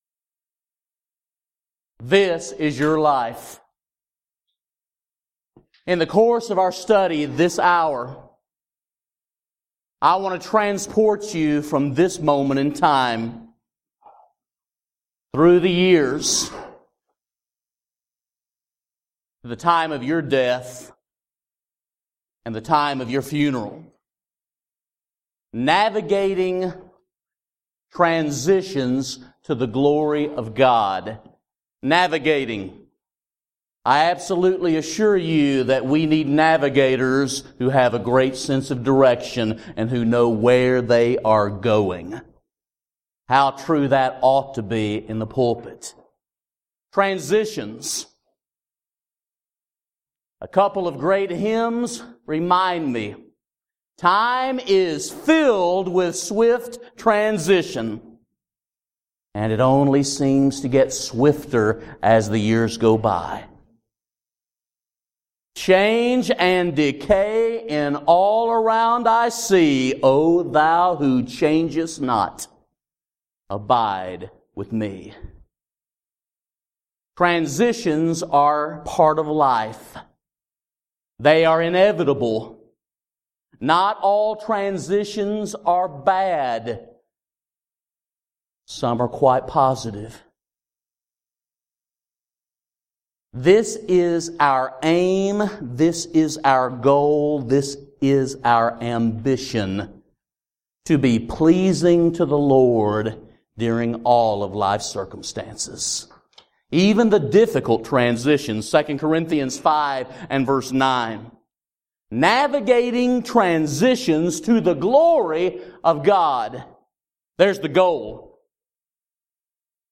Preacher's Workshop